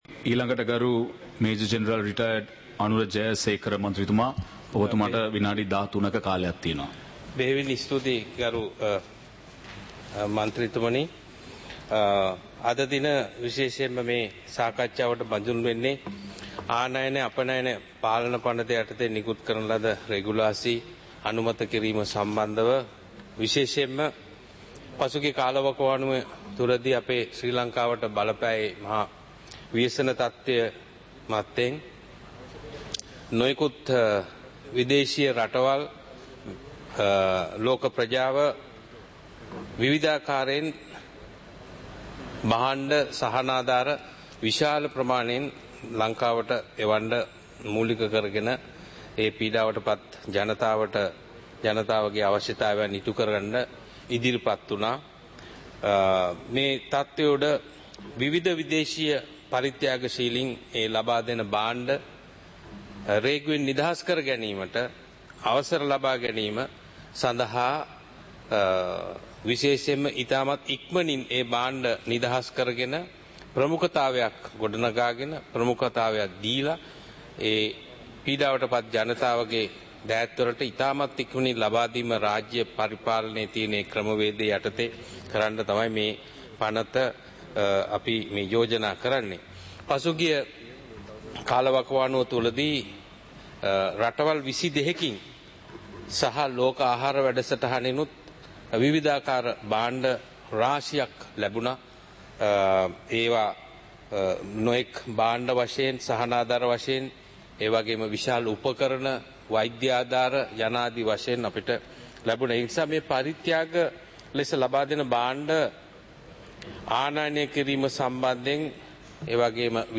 சபை நடவடிக்கைமுறை (2026-01-09)